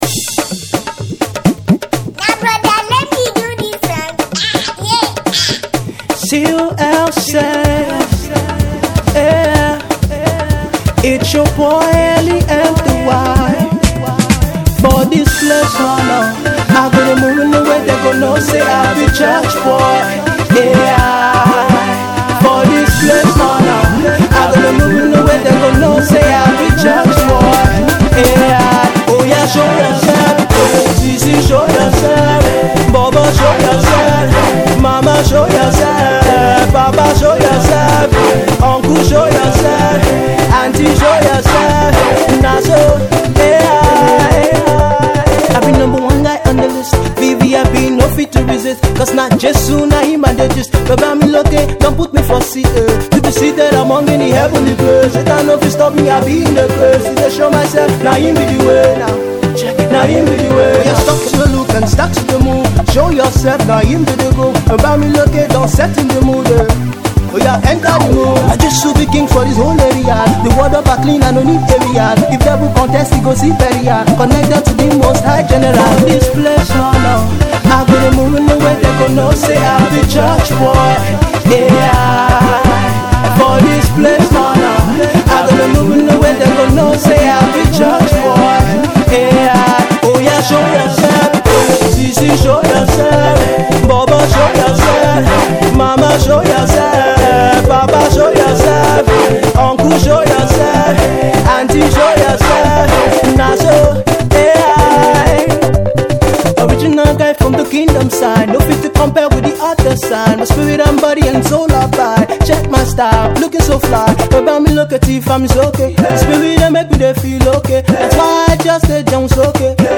dance song
The groovy tune will get you going.